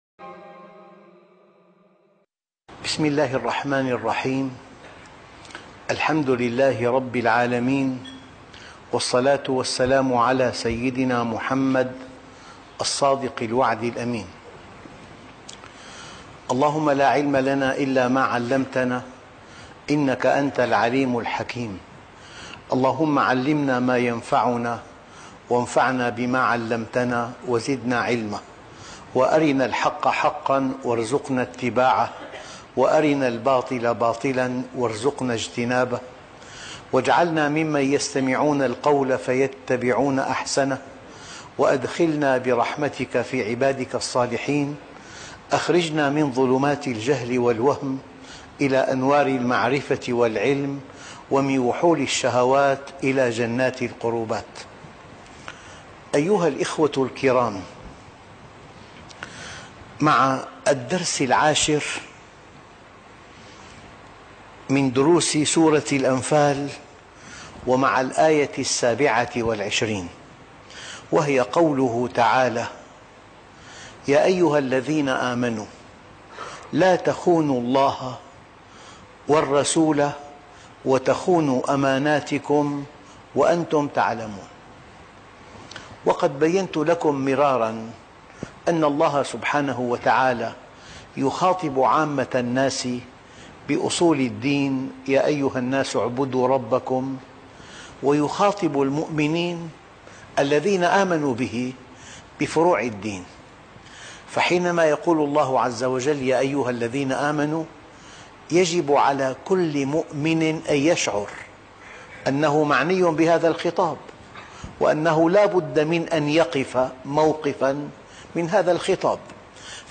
الدرس ( 10) تفسير سورة الأنفال - الشيخ محمد راتب النابلسي